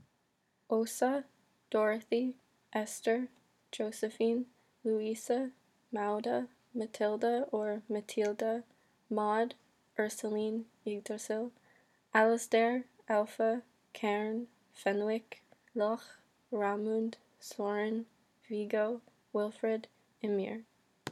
My pronunciations
Aase - oh sah
[name_f]Magda[/name_f] - maow dah (Danish pronunciation)
Yggdrasil - ihg dra sihl
[name_m]Fenwick[/name_m] - fen wihk (North American pronunciation)
[name_m]Ymir[/name_m] - a mix between ih meer & ee meer